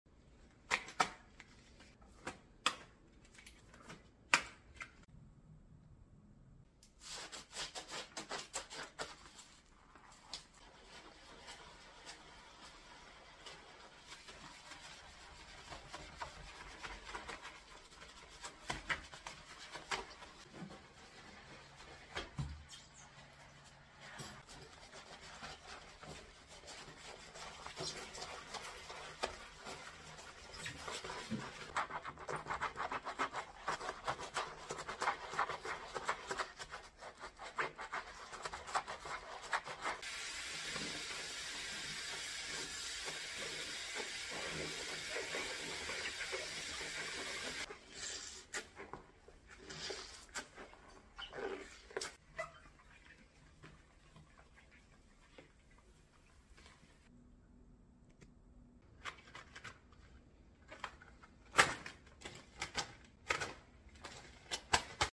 satisfying deep cleaning sink asmr sound effects free download